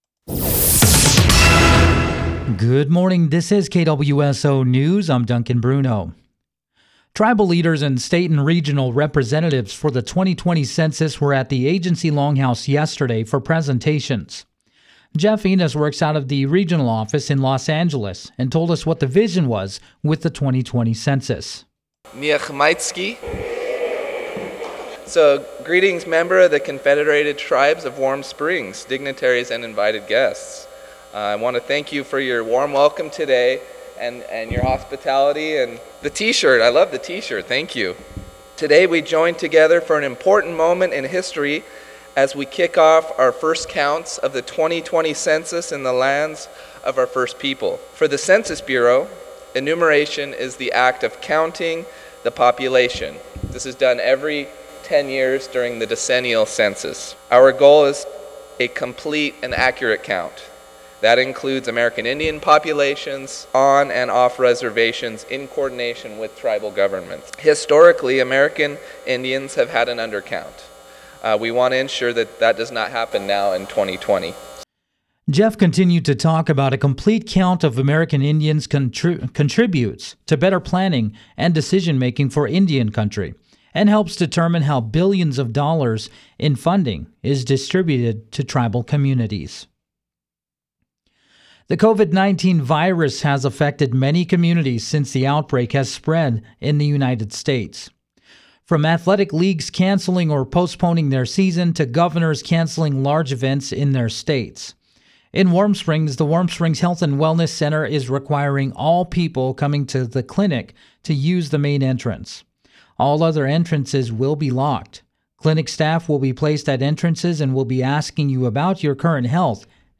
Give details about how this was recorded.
Tribal Leaders and State and Regional Representatives for the 2020 Census were at the Agency Longhouse yesterday for presentations.